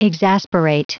Prononciation du mot exasperate en anglais (fichier audio)
Prononciation du mot : exasperate